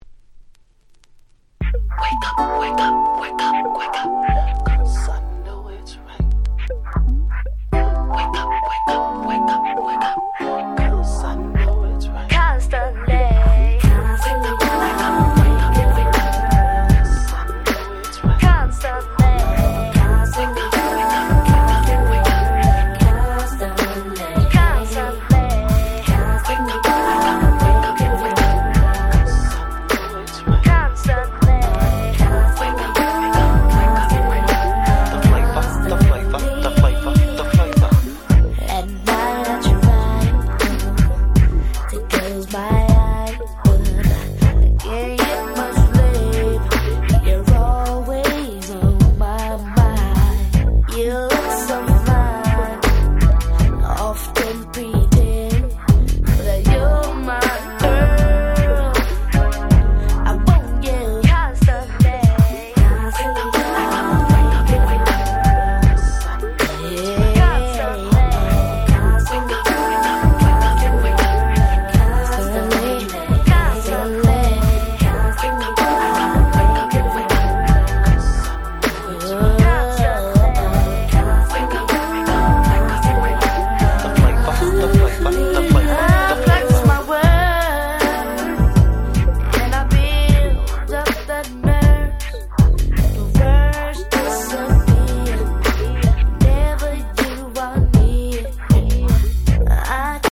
94' Super Hit Slow Jam !!